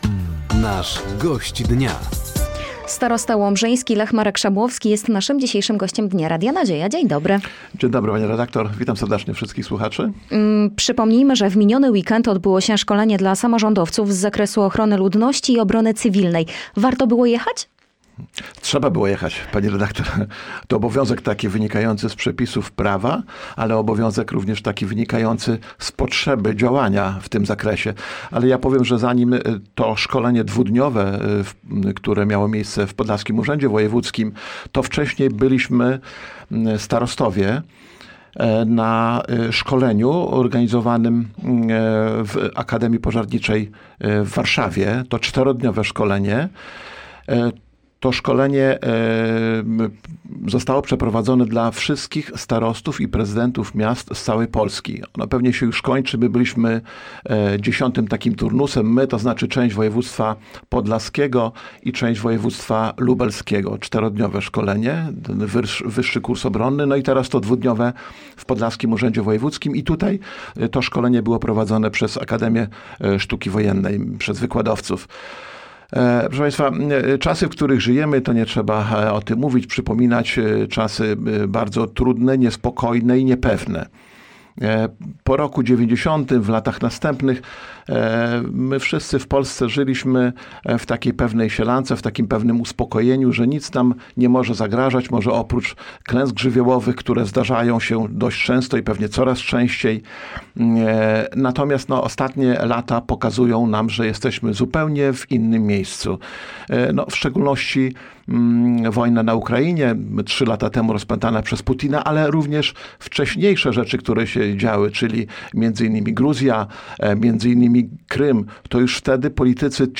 O minionym szkoleniu dla samorządowców z zakresu ochrony ludności i obrony cywilnej, bezpieczeństwie województwa podlaskiego oraz zbliżające się inwestycje w Powiecie Łomżyńskim – to główne tematy rozmowy podczas audycji ,,Gość Dnia”. Studio Radia Nadzieja odwiedził starosta łomżyński, Lech Szabłowski.